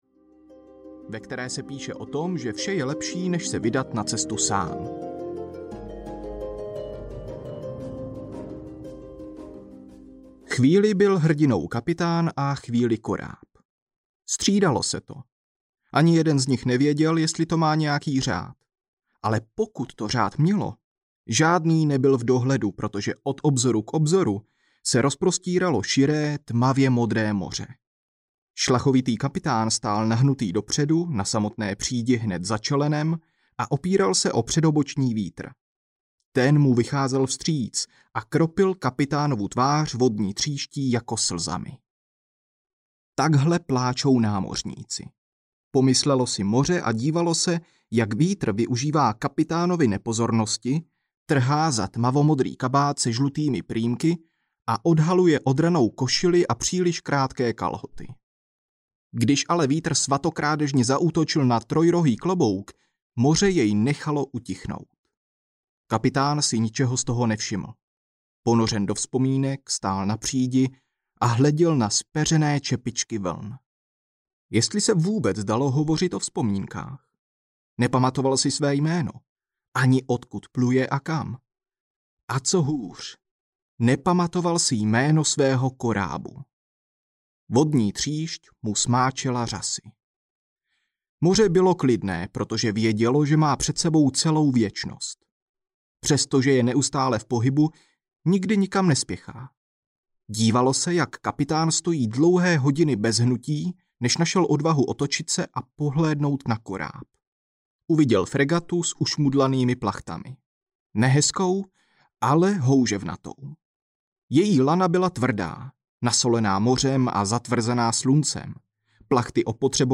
Jméno korábu audiokniha
Ukázka z knihy